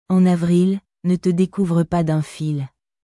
En avril, ne te découvre pas d’un filアォン アヴリル ヌ トゥ デクゥーヴル パ ドァン フィル